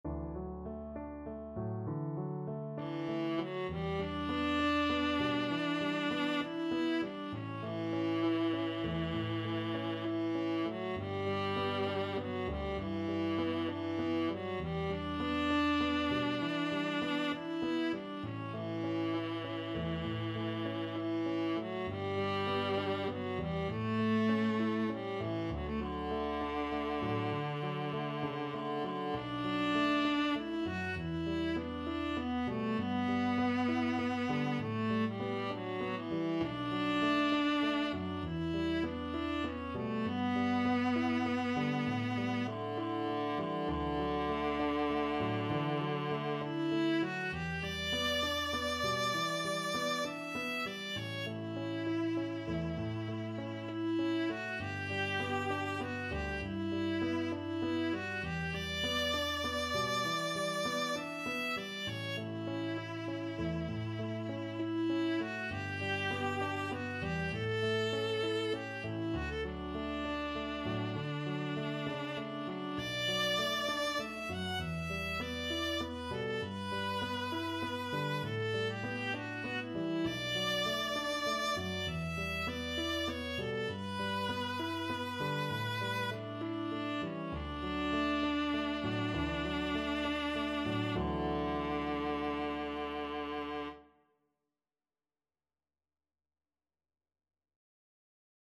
Viola version
6/8 (View more 6/8 Music)
Classical (View more Classical Viola Music)